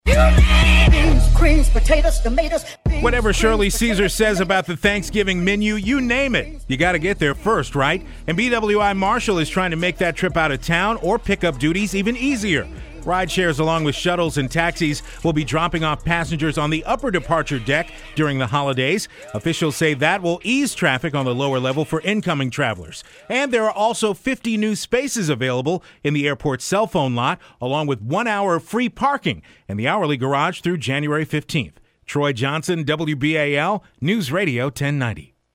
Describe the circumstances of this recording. A story I filed in November of 2017, on WBAL NewsRadio 1090. Baltimore-Washington International Thurgood Marshall Airport is making some changes to limit traffic congestion, to ease holiday backups.